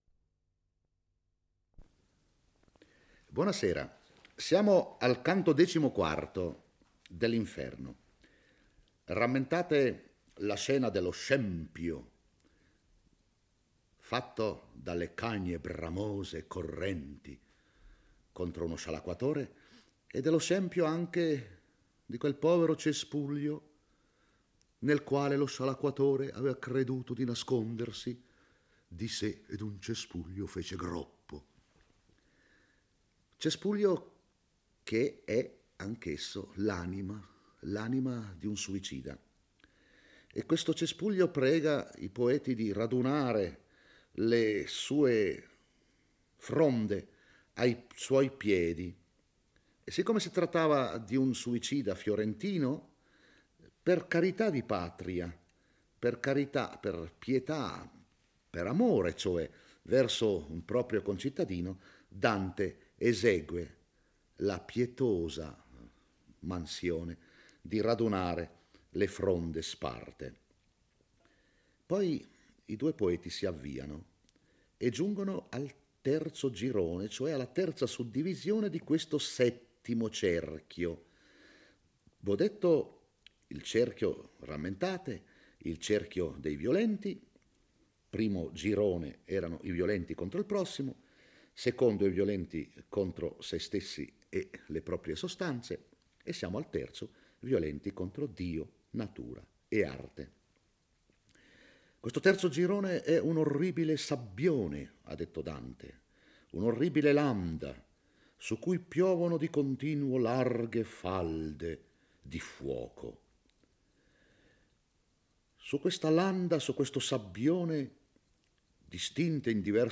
legge e commenta il XIV canto dell'Inferno. Dante e Virgilio giungono al terzo girone del settimo cerchio, il paesaggio è costituito da una distesa di sabbia arida e spessa, sulla quale numerosi gruppi di anime nude piangono miseramente mentre dall'alto scendono larghe falde di fuoco che incendiano il sabbione e tormentano senza sosta i dannati. È il girone dei violenti contro Dio, divisi in bestemmiatori, sodomiti e usurai. Dante incontra Capaneo, uno dei sette re che avevano assediato Tebe e che era spregiatore di tutti.